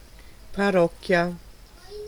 Ääntäminen
Synonyymit cure Ääntäminen France Tuntematon aksentti: IPA: /pa.ʁwas/ Haettu sana löytyi näillä lähdekielillä: ranska Käännös Ääninäyte Substantiivit 1. parrocchia {f} Muut/tuntemattomat 2. parrocchiani {m} Suku: f .